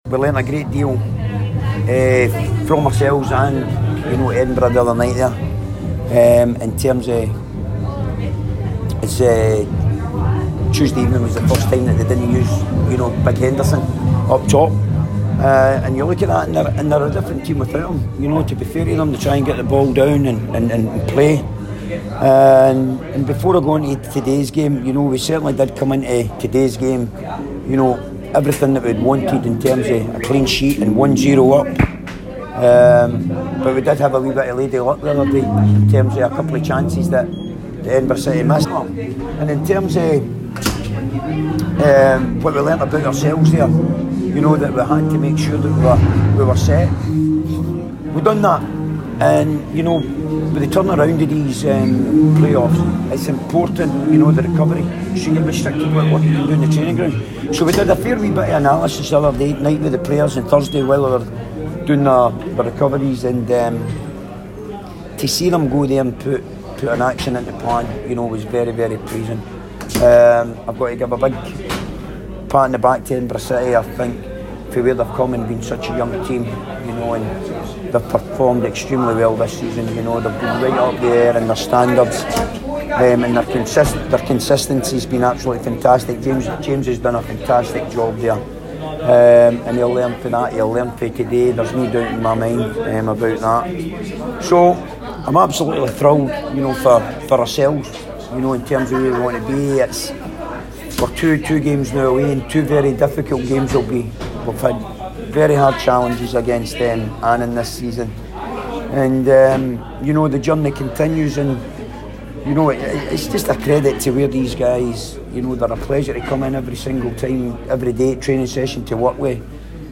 press conference after the Ladbrokes League 1 play-off match.